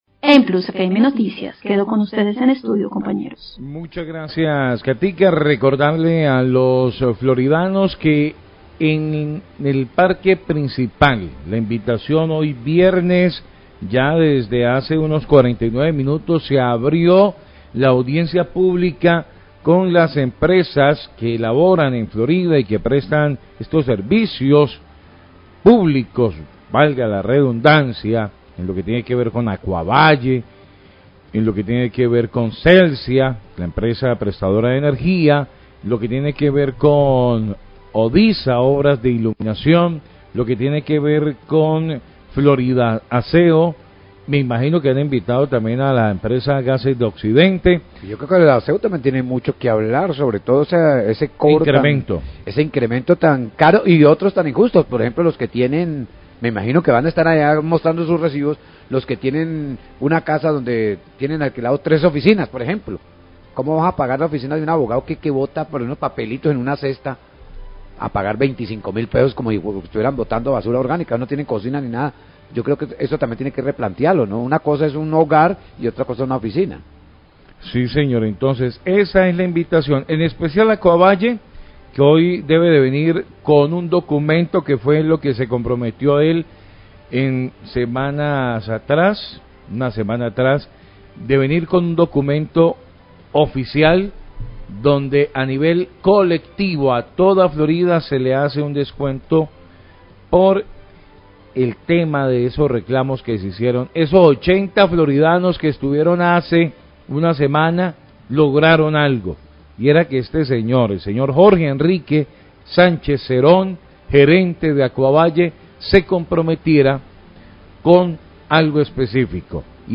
Radio
Periodista recuerda a los floridanos la invitacón para participar en la audiencia pública con las empresas de servicios públicos domiciliarios ne la plaza del pueblo; la cual se inicia con Acuavalle, luego Celsia, Gases de Occidente y la empresa de alumbrado público, Odisa.